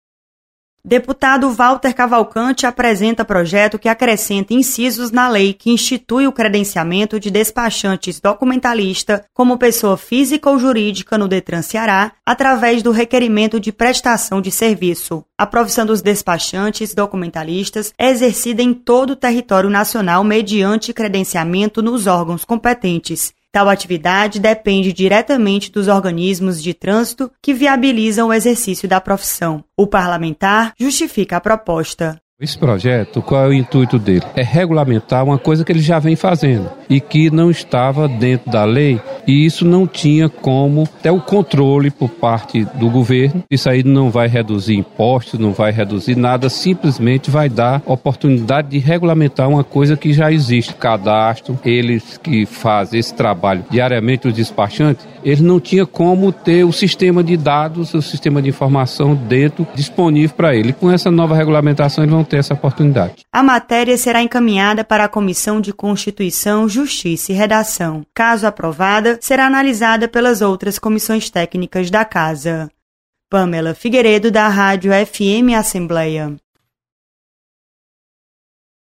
Projeto de lei regulamenta profissão de despachantes. Repórter